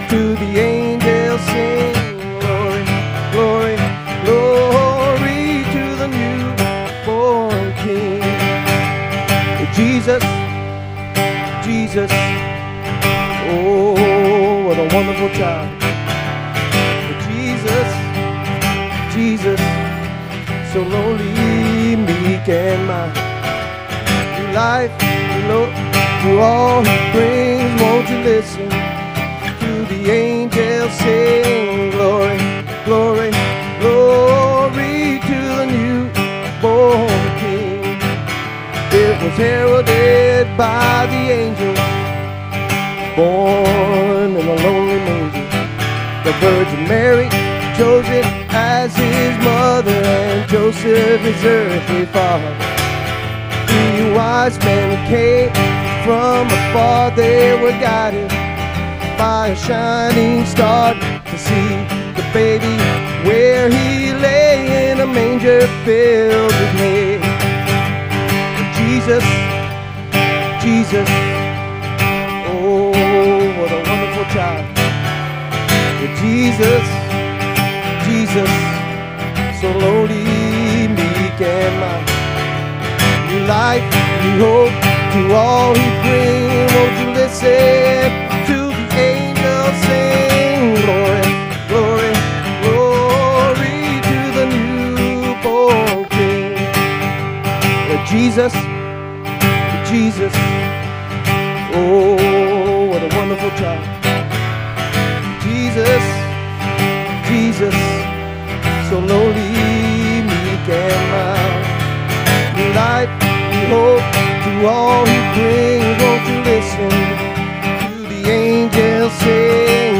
SERMON DESCRIPTION On a dark night, fearful shepherds heard heaven’s message: “Fear not…